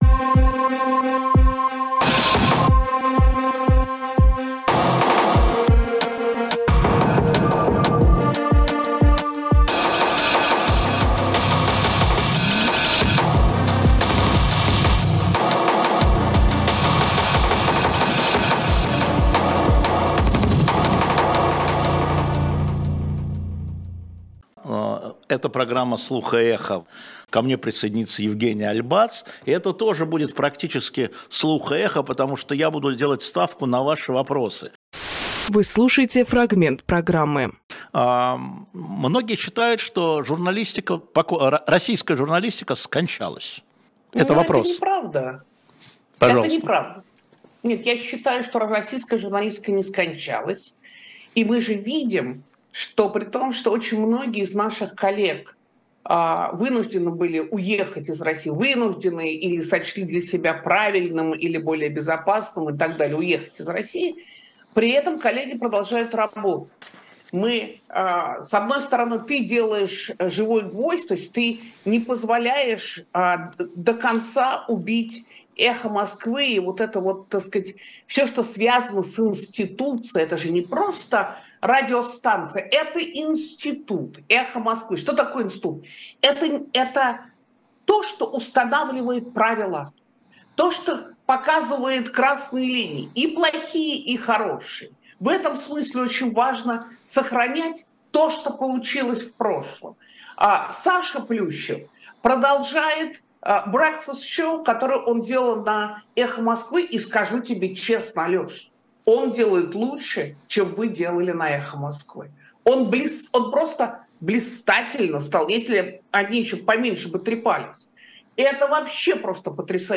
Фрагмент эфира от 21.09.23